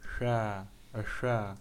labzd voiceless labiodental fricative
[fʷ] Hadza, Chaha
Labialized_voiceless_labiodental_fricative.ogg.mp3